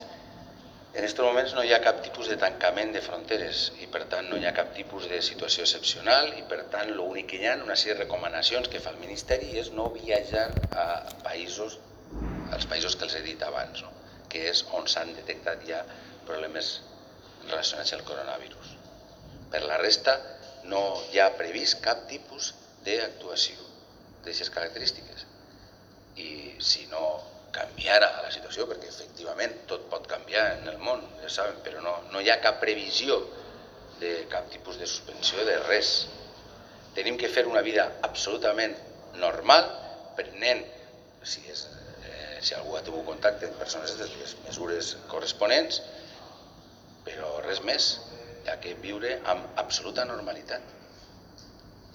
Diversos cortes de audio de las declaraciones de Ximo Puig en la mañana del día de hoy.